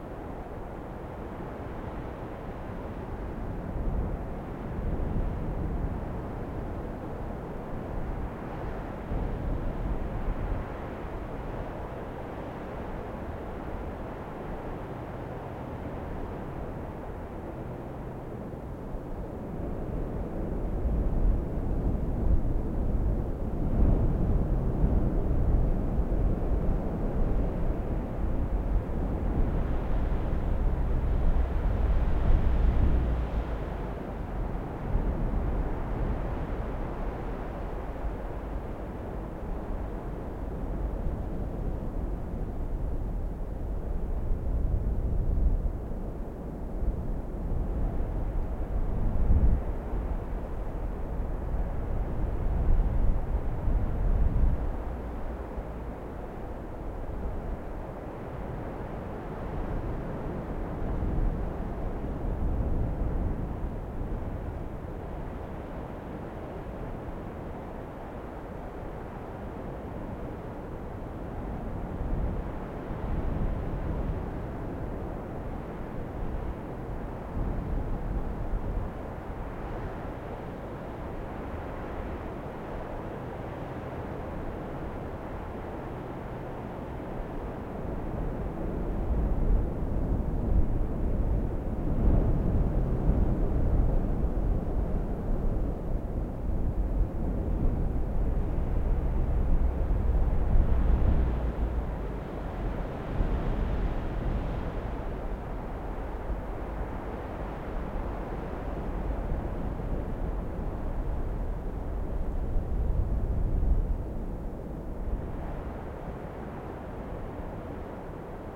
windLightLoop.ogg